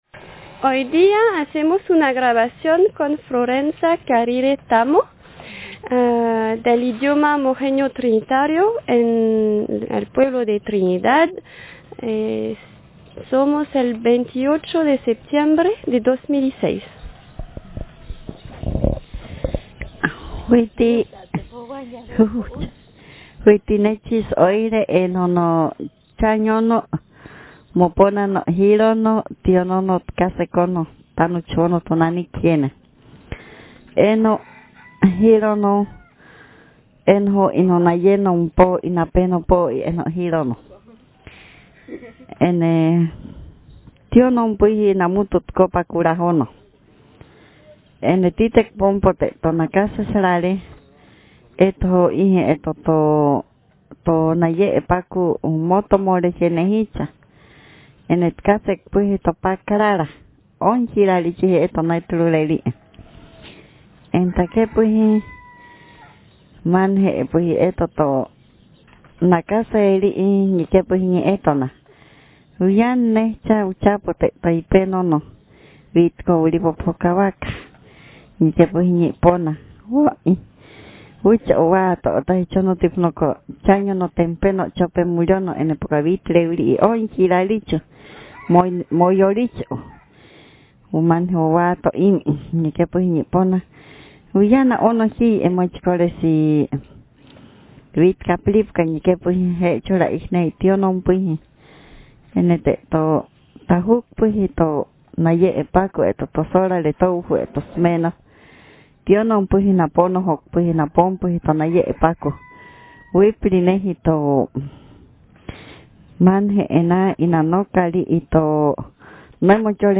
Speaker sex f Text genre traditional narrative